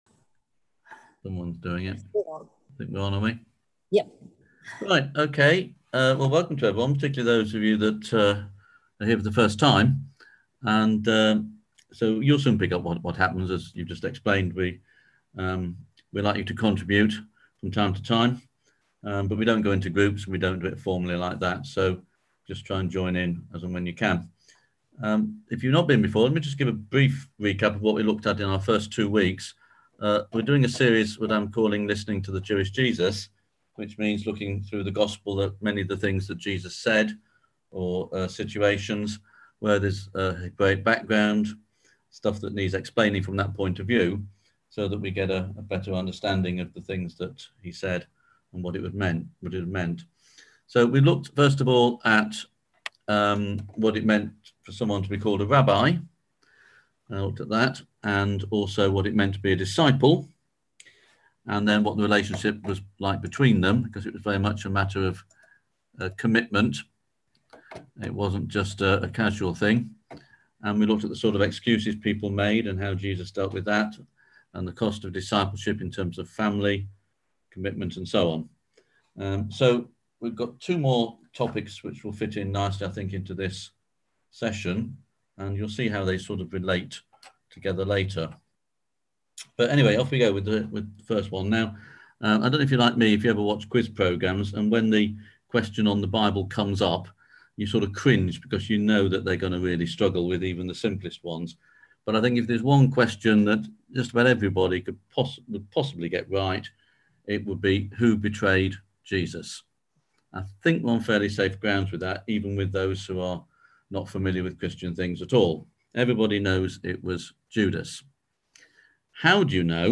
On JANUARY 21st at 7pm – 8:30pm on ZOOM